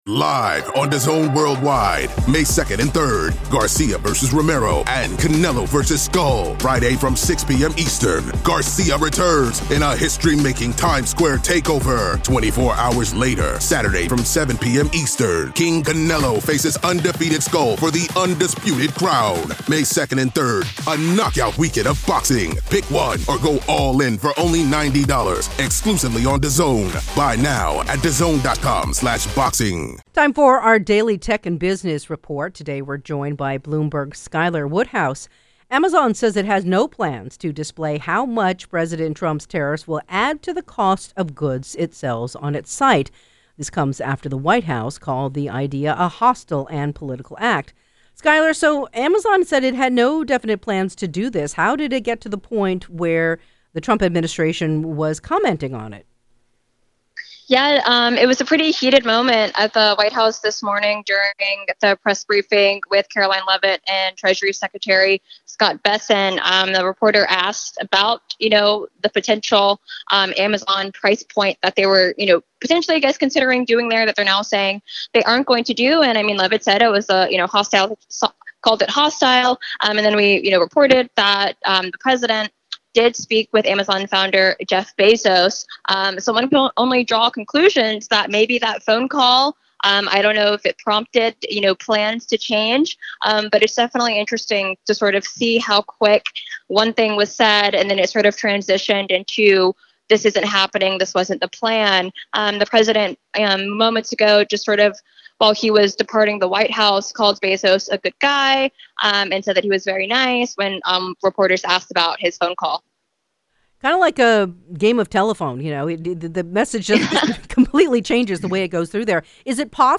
Time now for our daily Tech and Business Report.